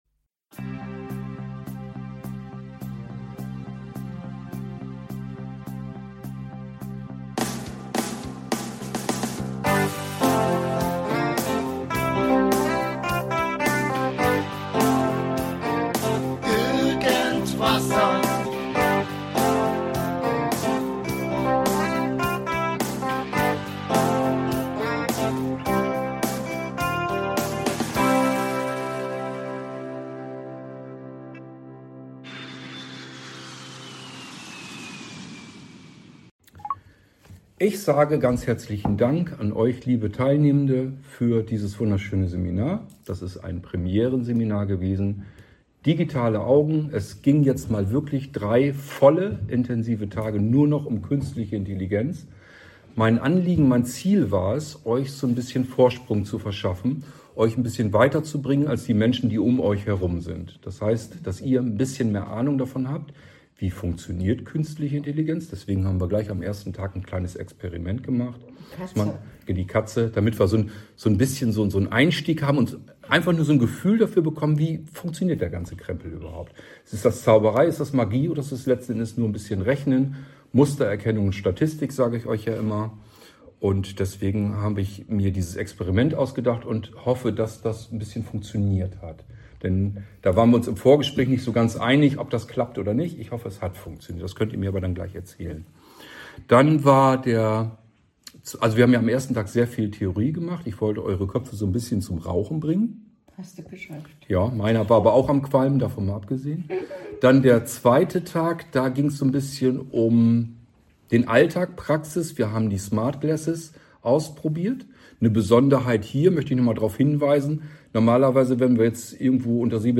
Vom 7. März bis zum 11. März 2026 fand unser Premierenseminar rund um die und mit der künstlichen Intelligenz in Rochsburg statt. Und hier erzählen die Teilnehmenden, wie sie es fanden.